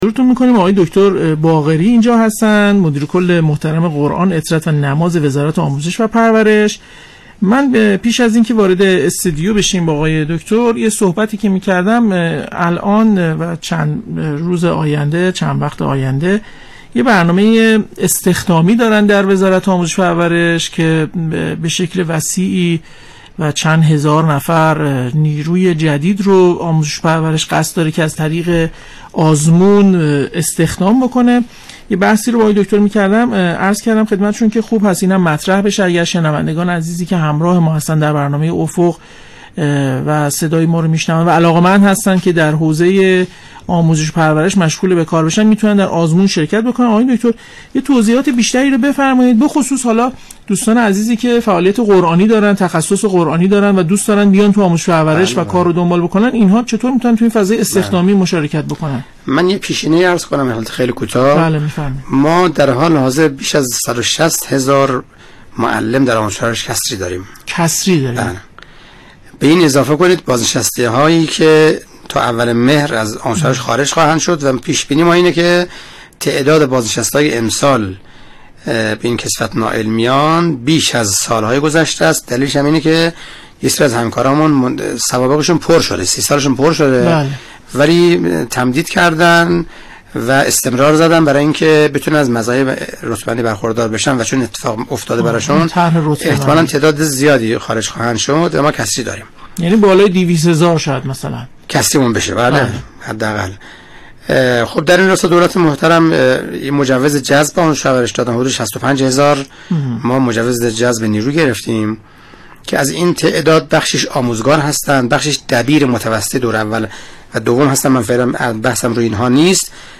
میکائیل باقری، مدیرکل قرآن، عترت و نماز وزارت آموزش و پرورشبه گزارش خبرنگار ایکنا، برنامه زنده افق، امروز، 9 اردیبهشت‌ماه با حضور میکائیل باقری، مدیرکل قرآن، عترت و نماز وزارت آموزش و پرورش با موضوع بررسی فعالیت‌های قرآنی و دینی در مدارس و آموزشگاه‌ها به روی آنتن رادیو قرآن رفت.
یادآور می‌شود، برنامه افق با محوریت پیگیری مطالبات رهبری از جامعه قرآنی، بررسی و آسیب‌شناسی فعالیت‌های قرآنی كشور، ارائه گزارش از برنامه‌های مختلف قرآنی در سطح كشور و گفت‌وگو با مسئولان قرآنی، شنبه‌ها، ساعت ۱۴:۲۵ از شبكه رادیویی قرآن پخش می‌شود.